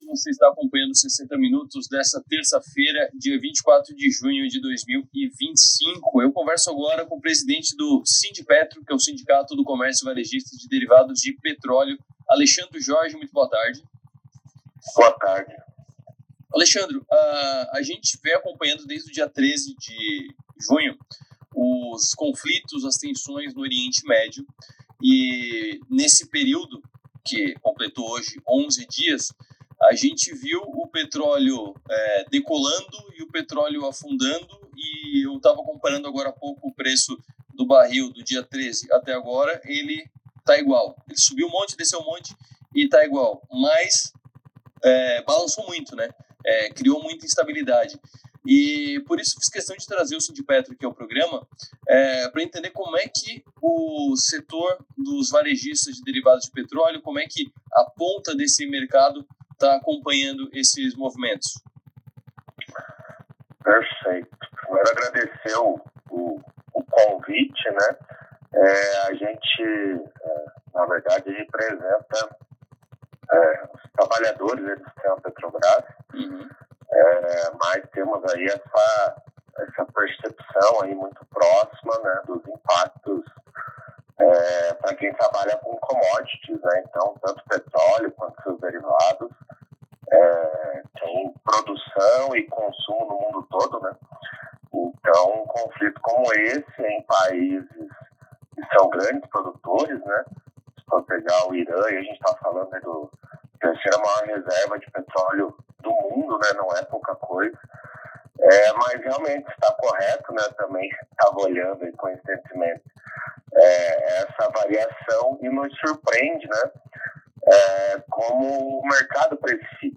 do programa 60 Minutos, da Rádio Som Maior, de Criciúma-SC.